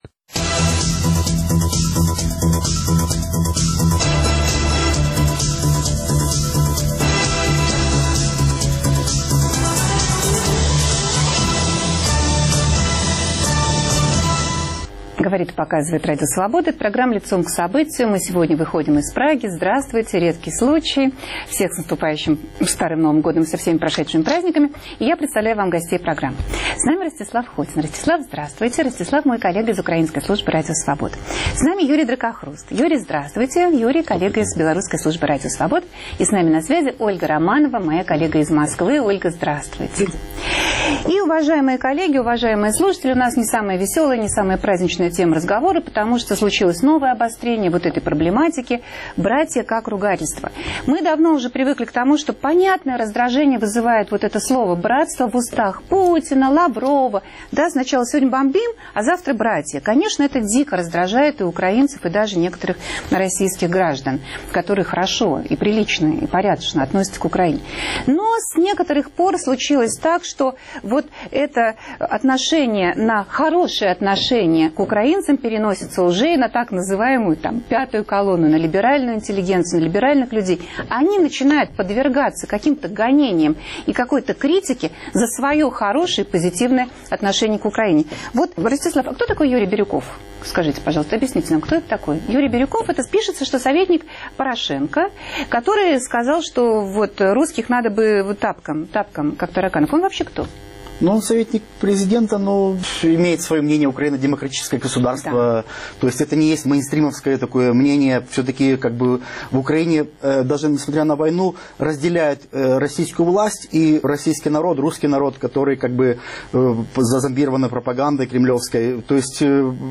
Как далеко зашло ненавистничество? Обсуждают журналисты